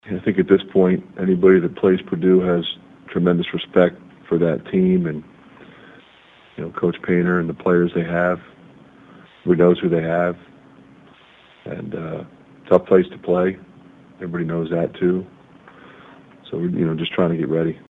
Iowa coach Fran McCaffery says Mackey Arena is always a tough place to play no matter what the Boilers are ranked.